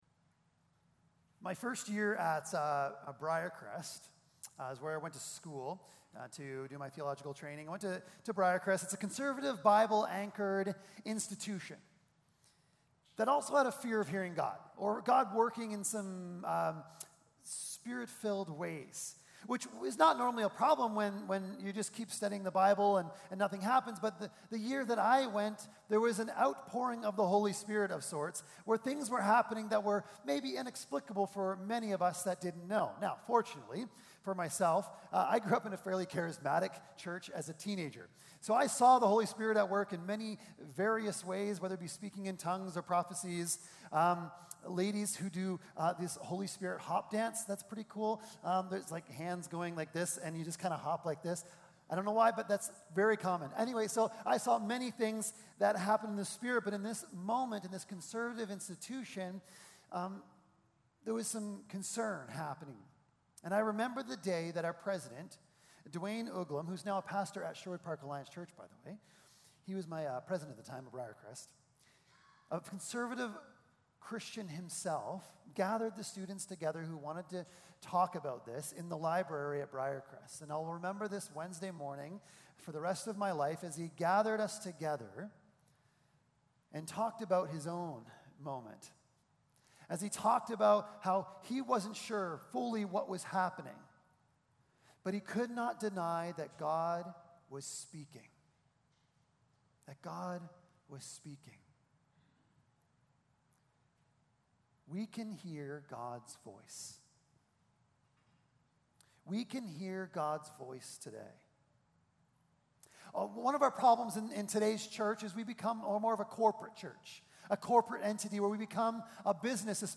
Passage: 1 Kings 19: 11-13 Service Type: Sunday Morning Service Passage